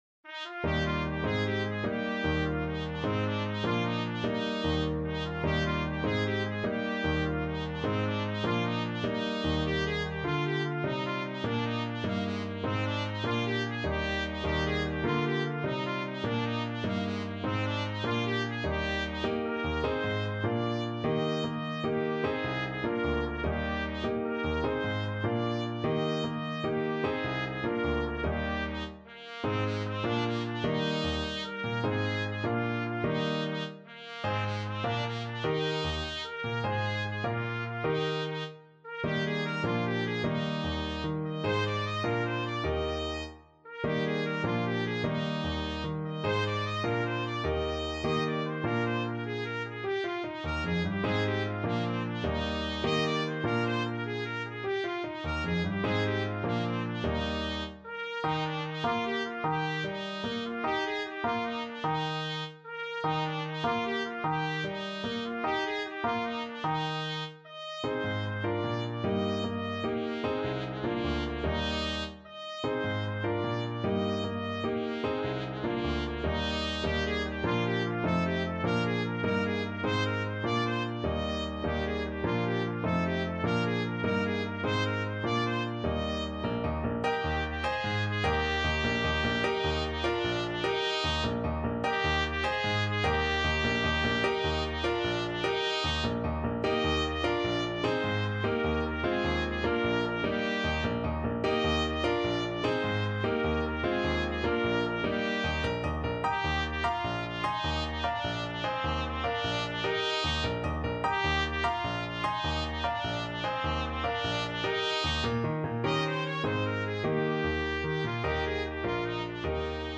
Trumpet
Eb major (Sounding Pitch) F major (Trumpet in Bb) (View more Eb major Music for Trumpet )
6/8 (View more 6/8 Music)
Classical (View more Classical Trumpet Music)